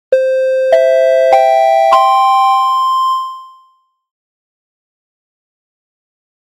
SE（呼び出し）